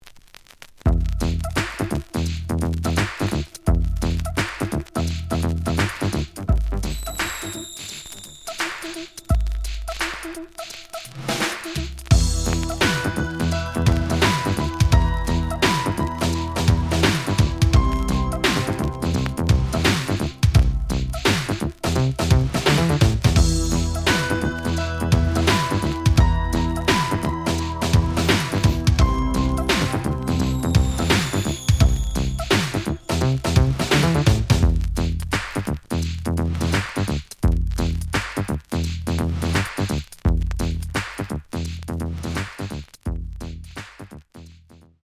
(Instrumental)
The audio sample is recorded from the actual item.
●Genre: Hip Hop / R&B